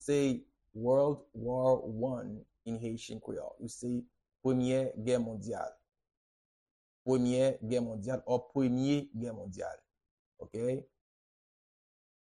Pronunciation and Transcript:
How-to-say-World-War-1-in-Haitian-Creole-Premye-Ge-Mondyal-pronunciation.mp3